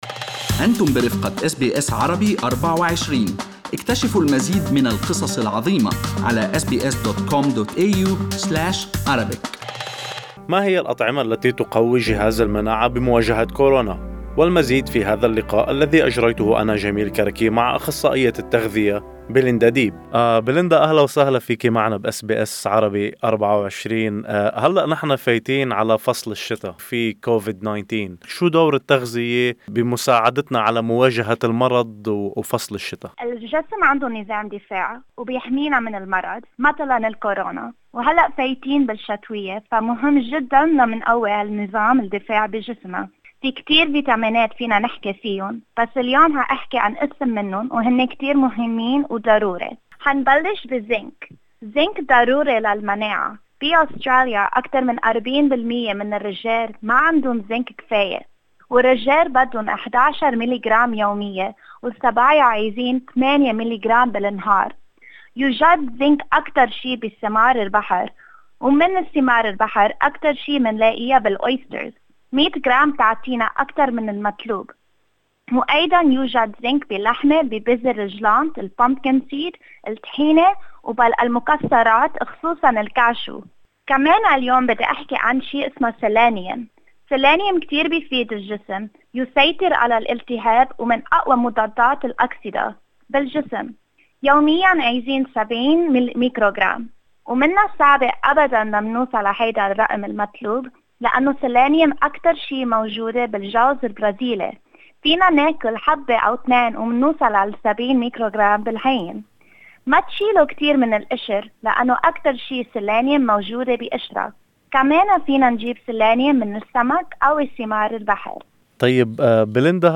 لمعرفة الأطعمة التي تحتوي على الزنك استمع إلى اللقاء الصوتي.